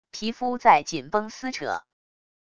皮肤在紧绷撕扯wav下载